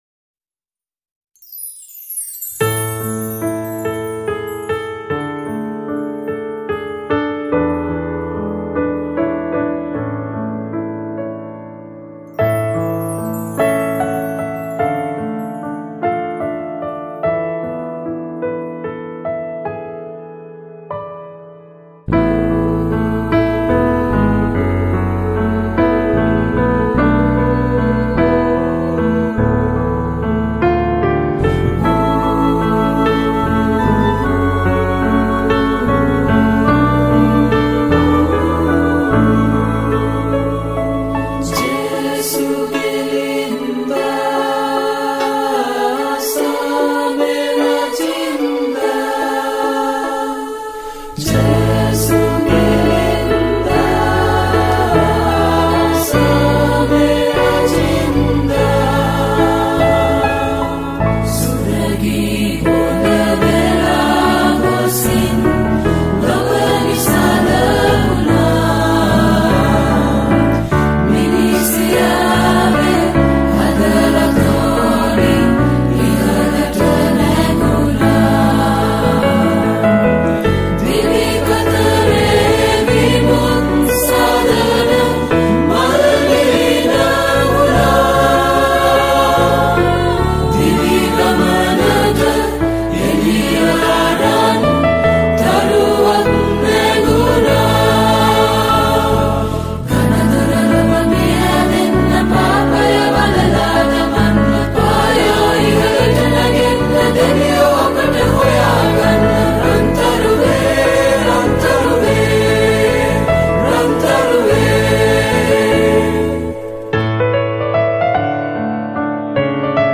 High quality Sri Lankan remix MP3 (6).